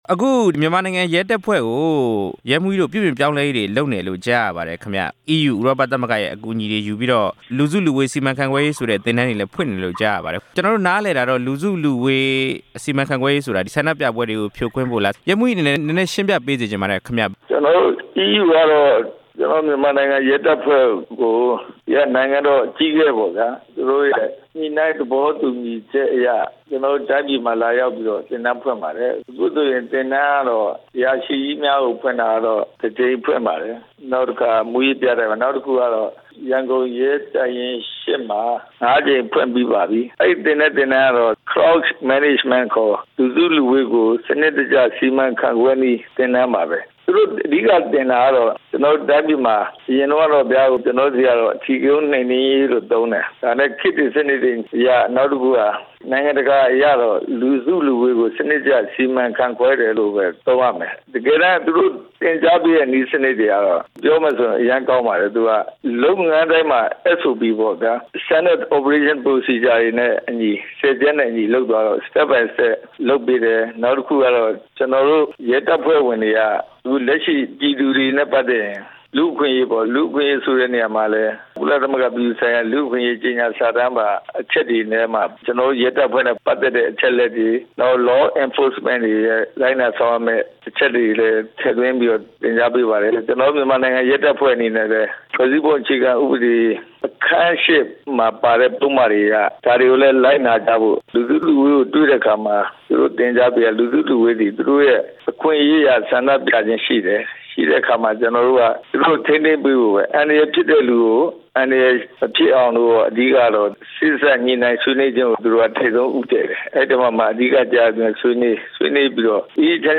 မြန်မာနိုင်ငံ ရဲတပ်ဖွဲ့ ပြုပြင်ပြောင်းလဲရေး ဆက်သွယ်မေးမြန်းချက်